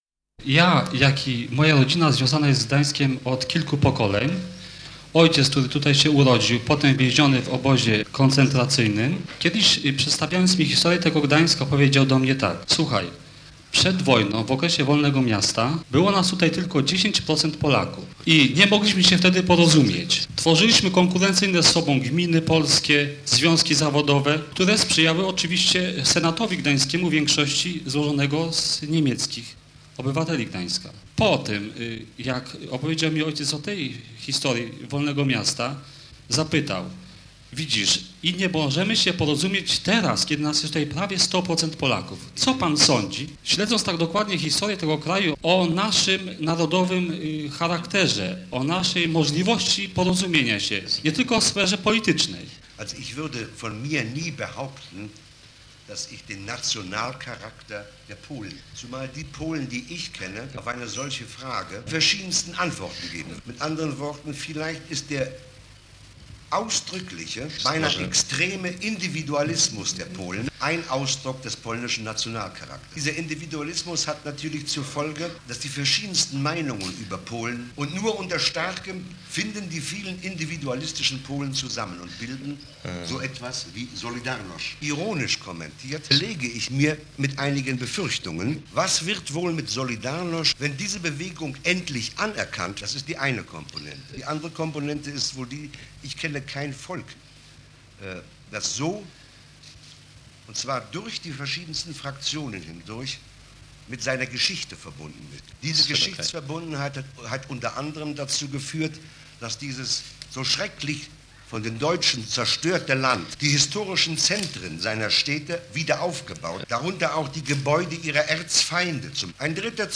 Spotkanie z Günterem Grassem w Ratuszu Staromiejskim w Gdańsku (fragment)